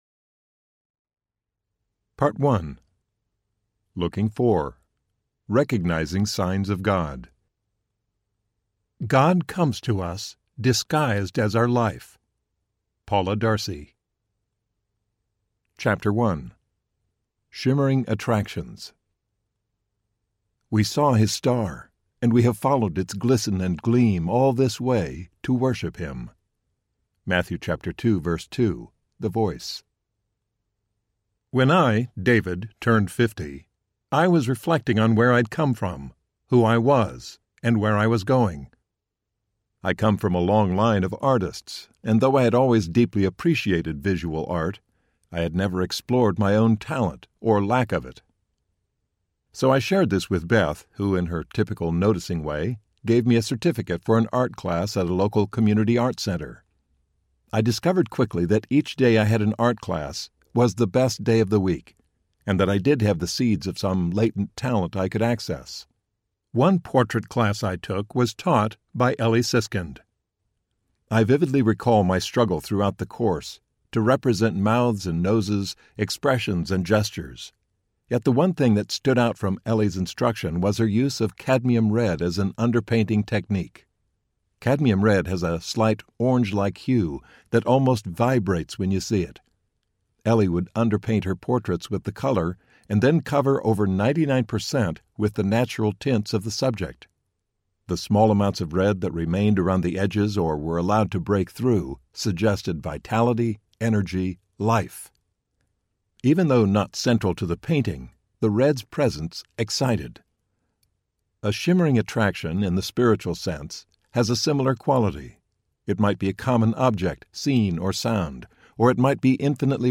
When Faith Becomes Sight Audiobook
Narrator
7.3 Hrs. – Unabridged